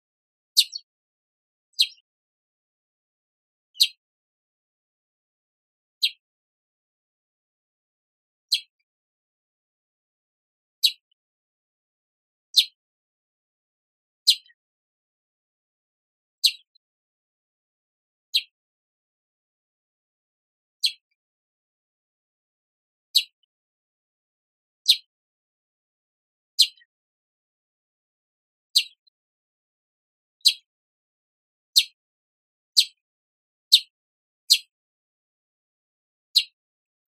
スズメのさえずりは、心を落ち着かせる自然の音です。
スズメのさえずり - 自然の音で癒される 着信音